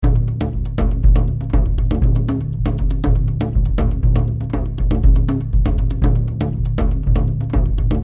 标签： 120 bpm Cinematic Loops Drum Loops 1.35 MB wav Key : Unknown
声道立体声